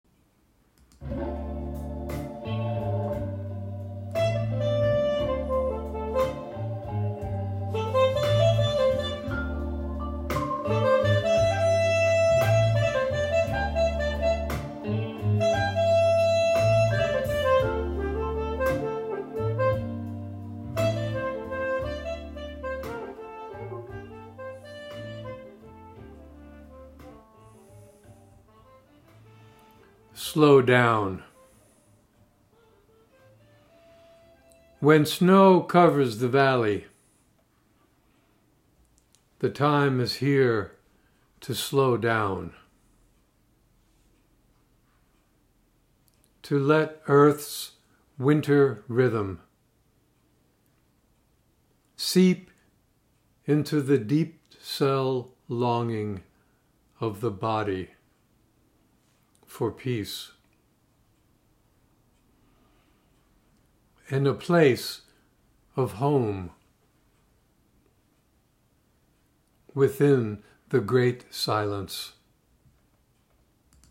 Reading of “Slow Down” with music by Van Morrison